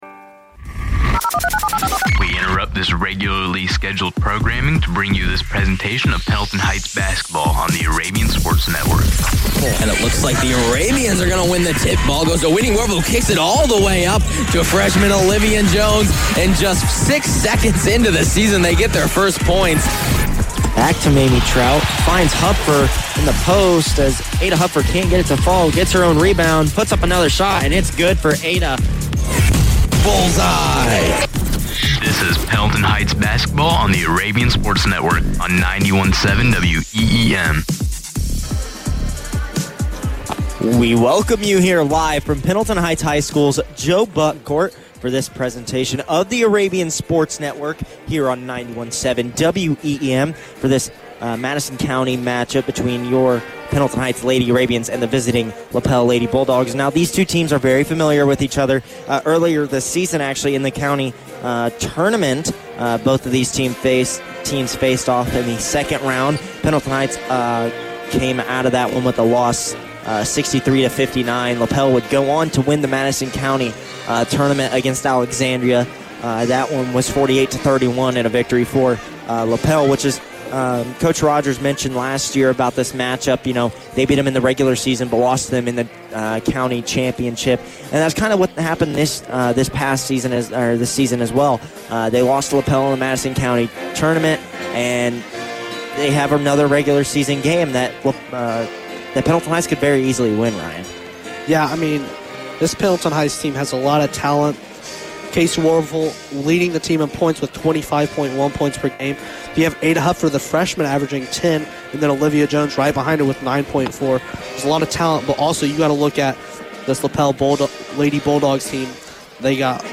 Varsity Girls Basketball Broadcast Replay Pendleton Heights vs. Lapel 1-16-24